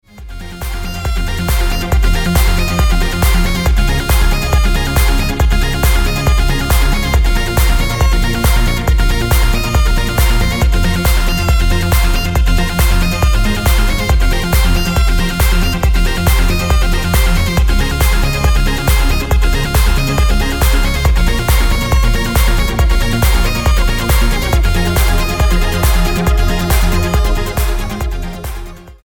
Dance/Electronic
Progressive Trance